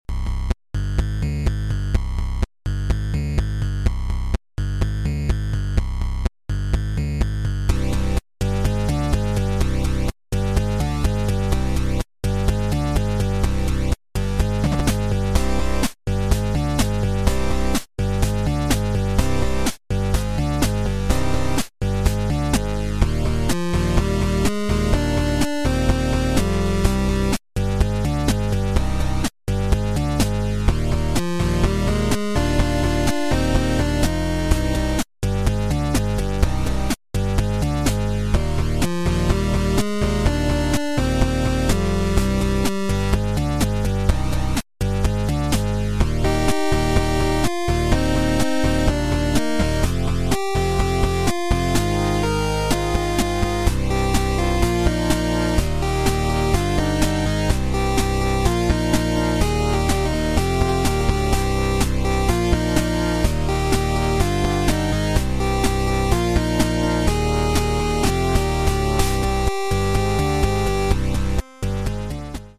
8Bit Cover